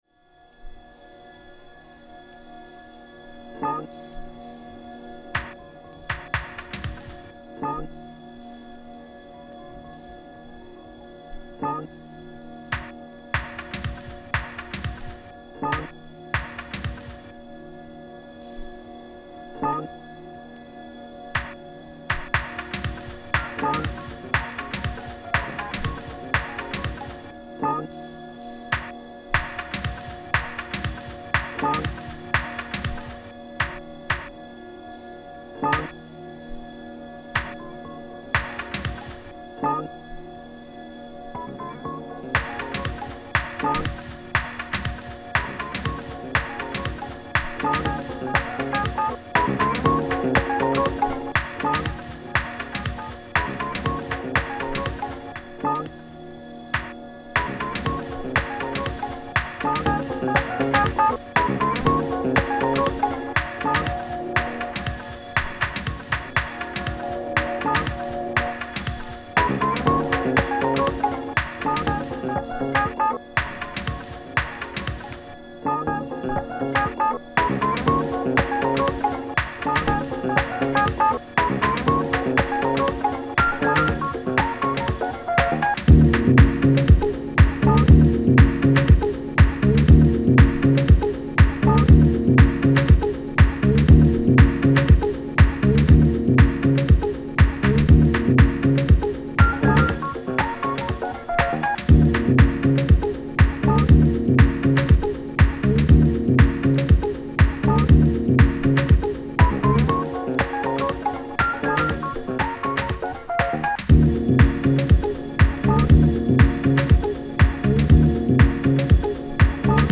サンプリングやコラージュを巧みに溶かし込み